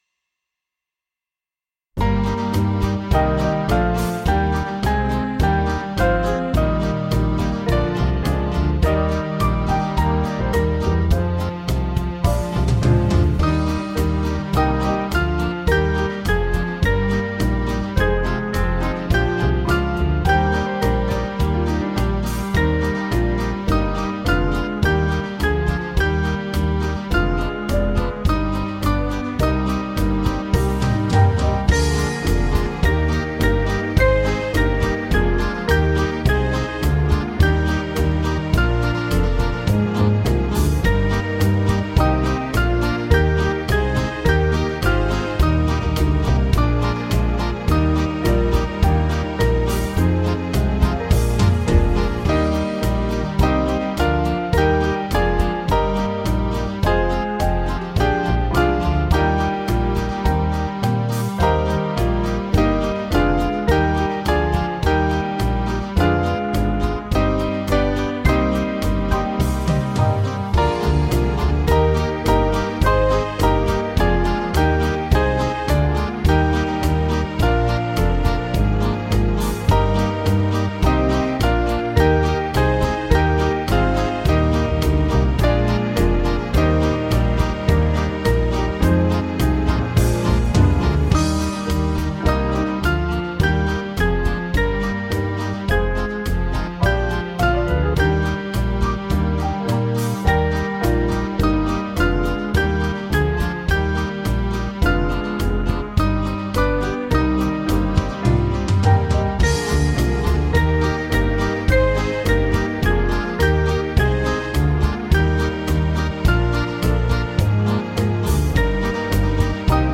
Small Band
(CM)   4/Eb 484.7kb